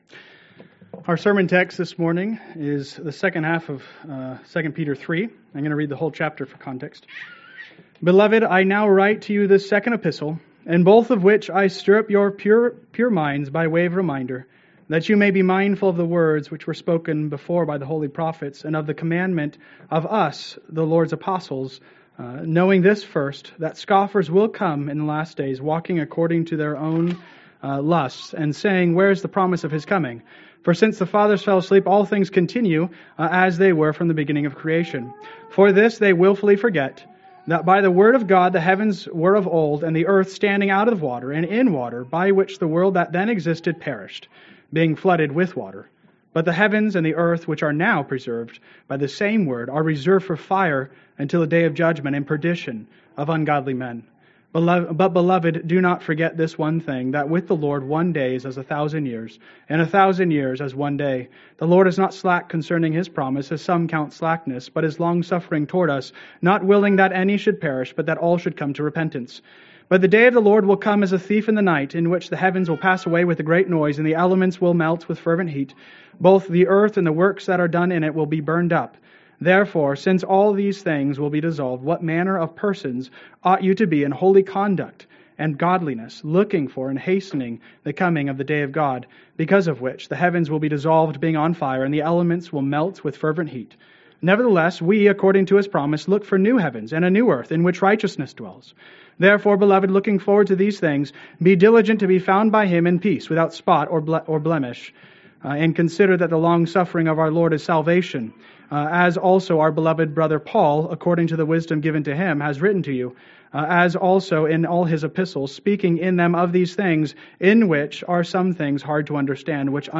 Sermon Outline: 11-24-24 Outline 2 Peter 3b (New Heavens & A New Earth)